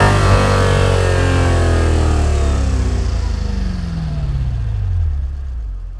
rr3-assets/files/.depot/audio/Vehicles/v8_12/v8_12_decel.wav
v8_12_decel.wav